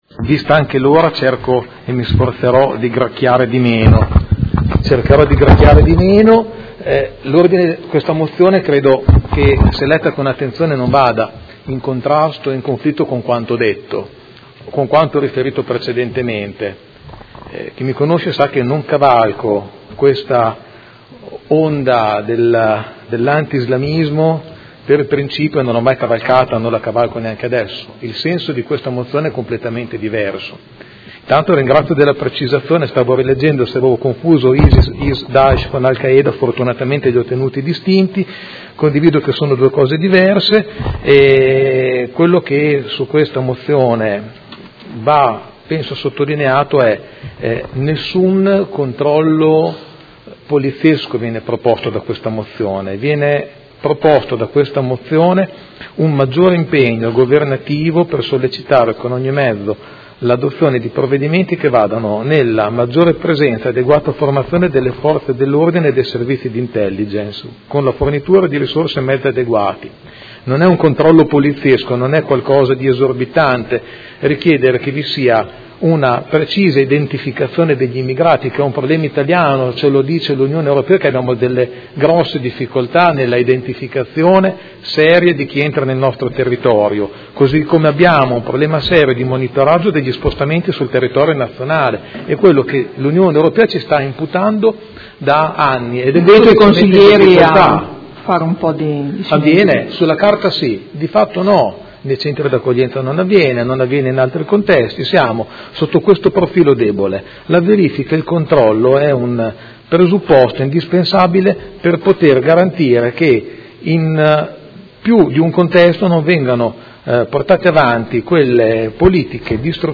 Seduta del 20/10/2016. Conclude dibattito su mozione presentata dai Consiglieri Pellacani e Galli (F.I.) avente per oggetto: Attacco terroristico a Nizza del 14 luglio 2016: sdegno e condanna per l’ennesima strage, solidarietà e vicinanza ai famigliari delle vittime, alla popolazione e al Governo Francese, necessità di interventi urgenti e concreti per arrestare il dilagare delle barbarie di matrice jihadista